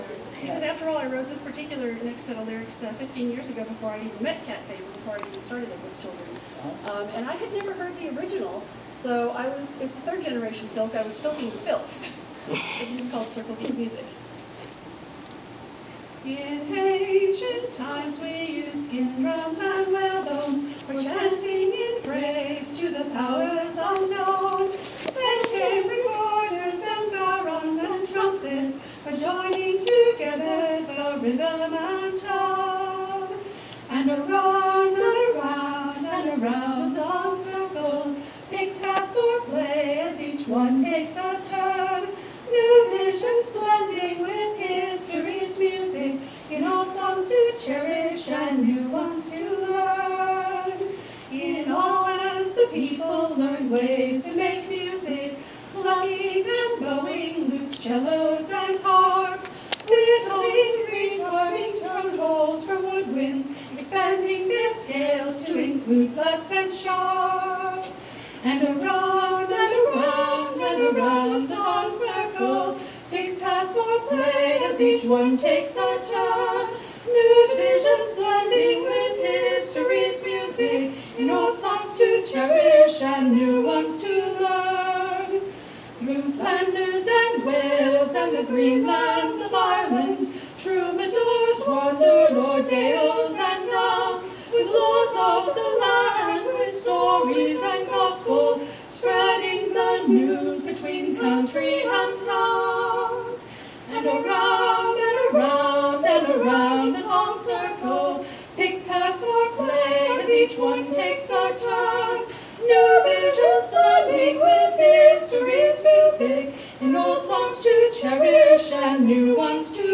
This concert was performed at Consonance 2007